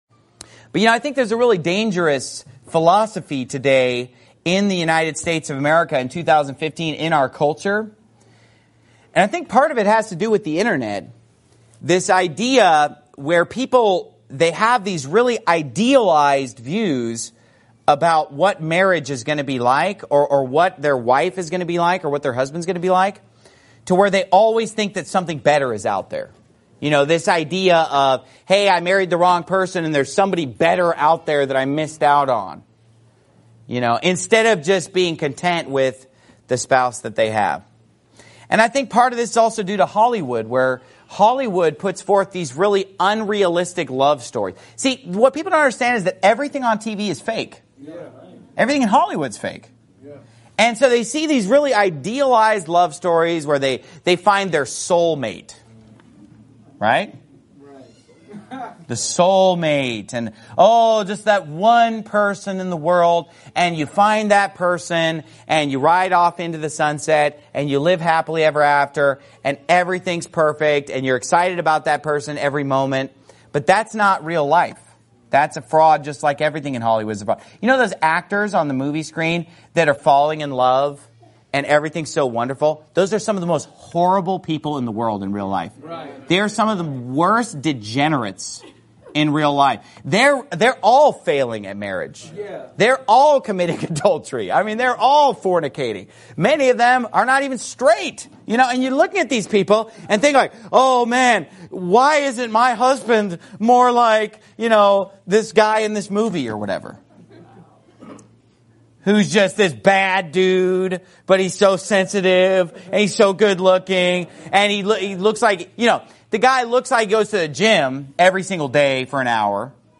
Sermon Clips Pastor Steven Anderson